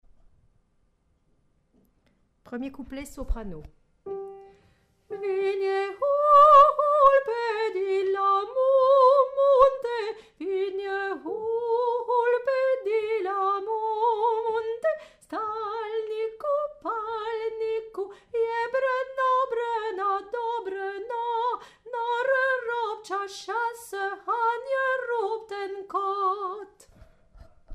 Les enregistrements partie par partie et pupitre par pupitre.
Soprano
colinda1_Soprano.mp3